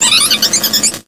Audio / SE / Cries / HELIOPTILE.ogg